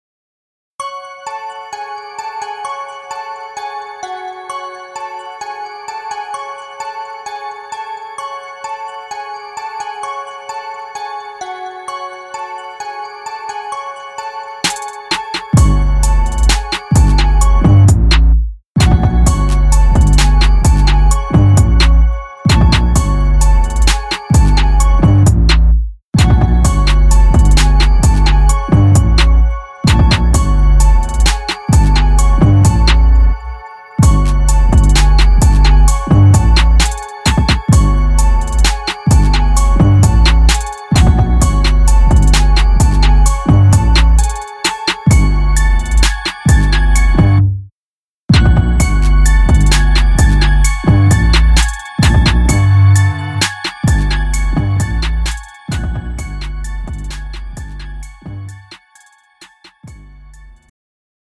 축가 및 결혼식에 최적화된 고품질 MR을 제공합니다!
음정 원키
장르 가요